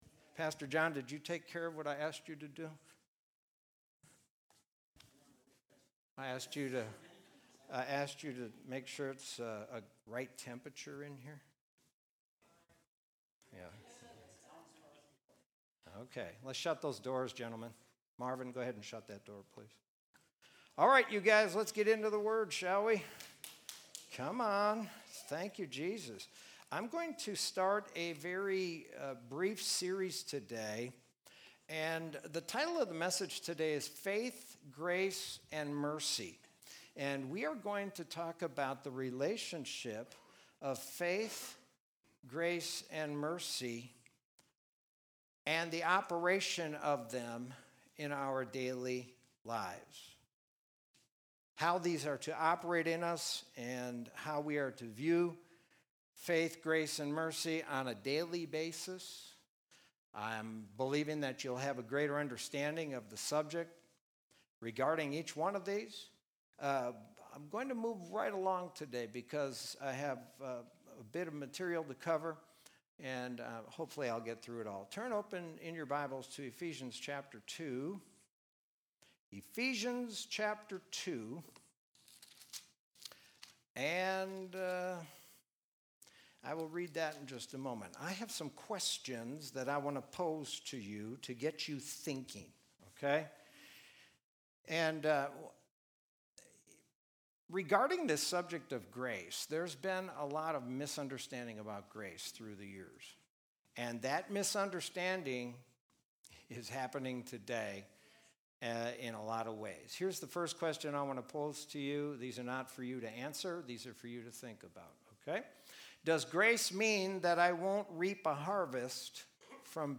Sermon from Sunday, June 7, 2020.